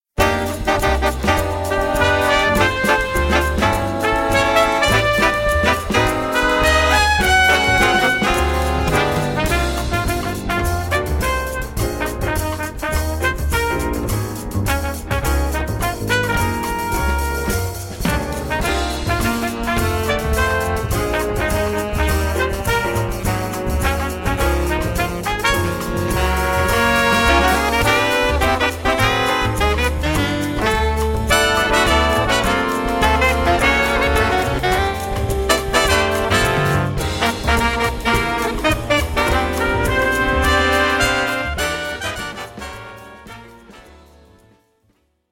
Trumpet/Flugel Horn
Trombone
Alto Saxophone
Tenor Saxophone
Baritone Saxophone
Piano
Bass
Drums/Percussion